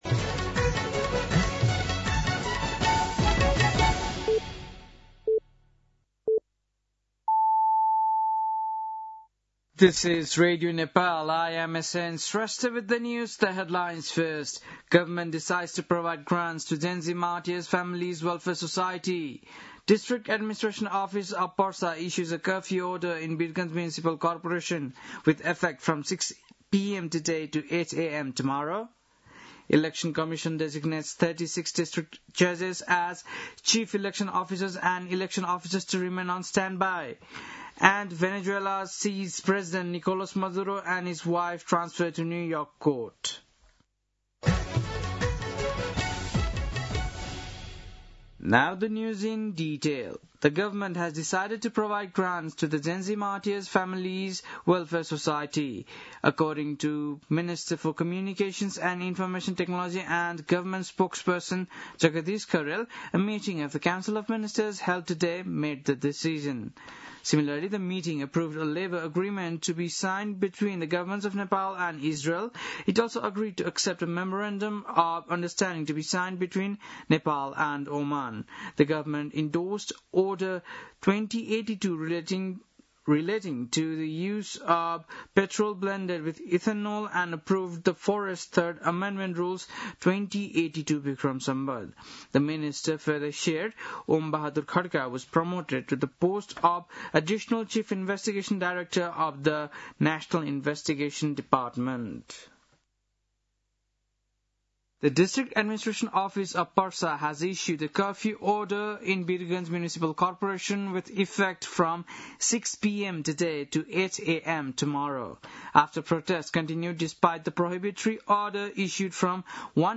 बेलुकी ८ बजेको अङ्ग्रेजी समाचार : २१ पुष , २०८२
8-pm-english-news-9-21.mp3